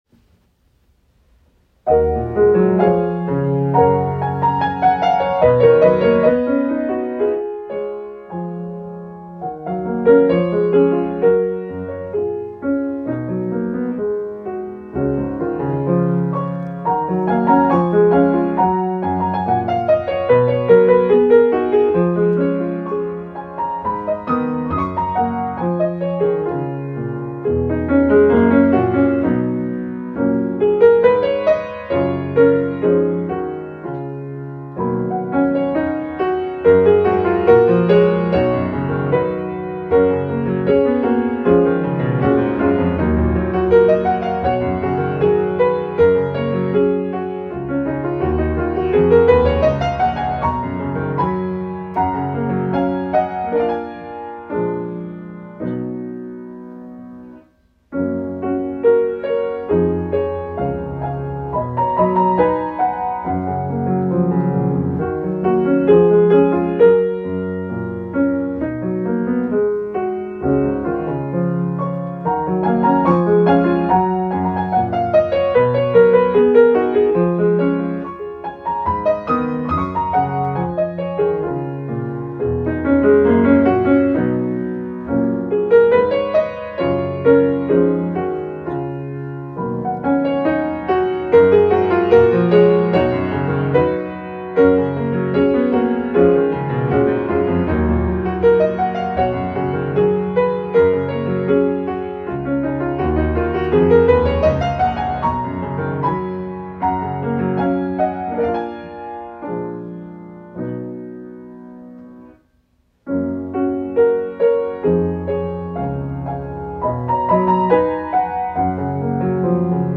Practice Tracks
Piano-track-Winter-dream.m4a